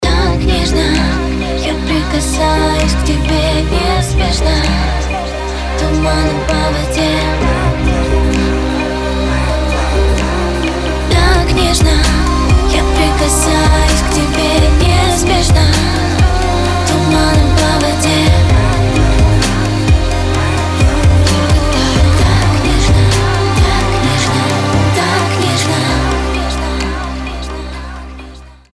• Качество: 320, Stereo
поп
dance
чувственные
красивый женский голос